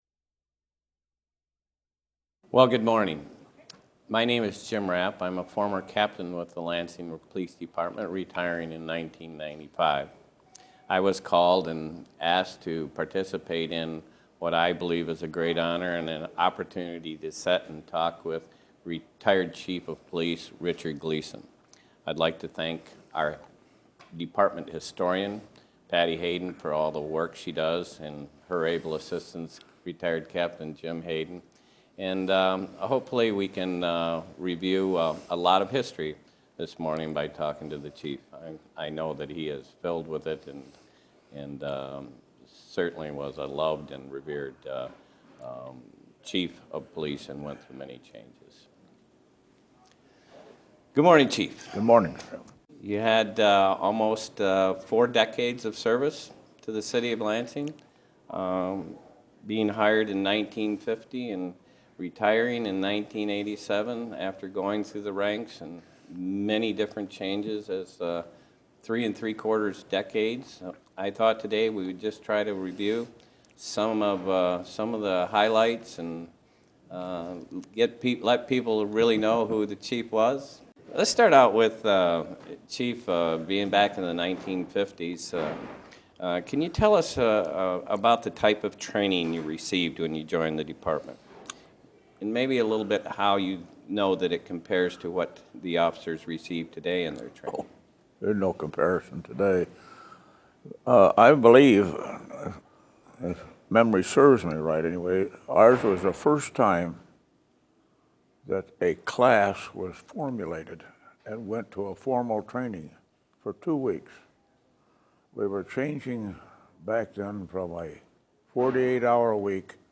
Interview of retired Lansing Police Chief Richard Gleason on the changes he made to the police force during his tenure as captain and the impact of changes made to the City of Lansing Charter to the Department